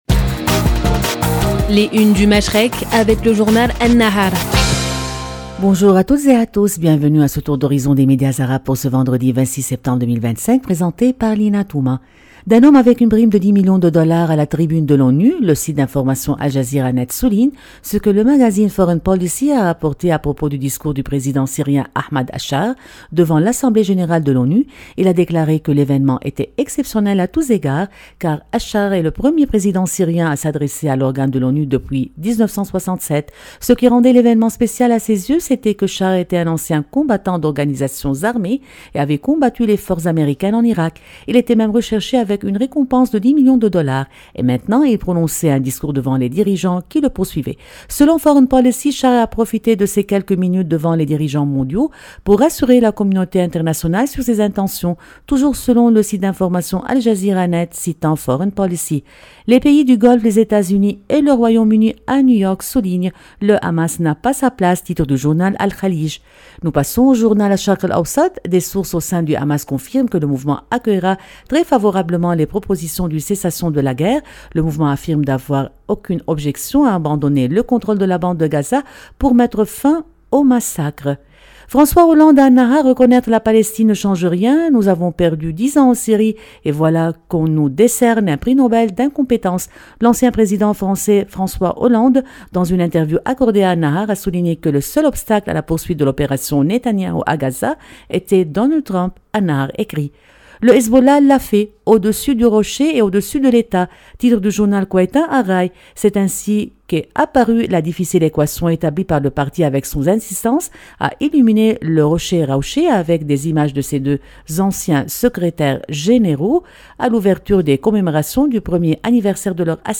Revue de presse des médias arabes